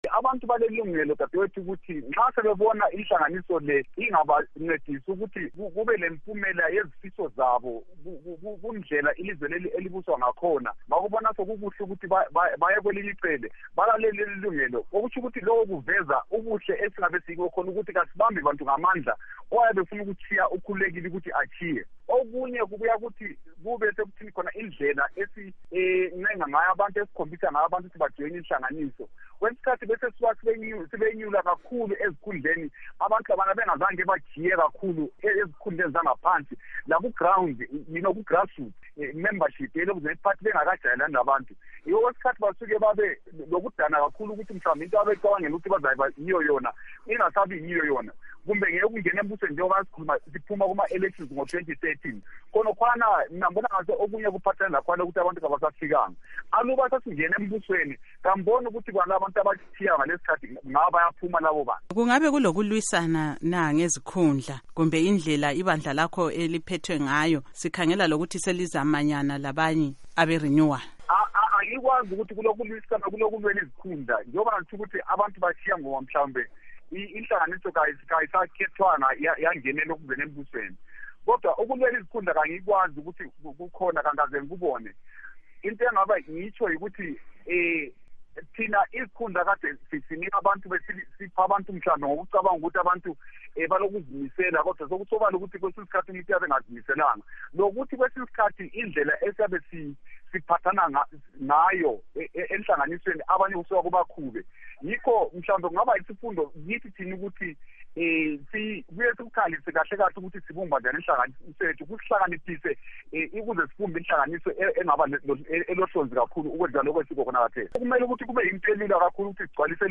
Ingxoxo lomnu. moses mzila ndlovu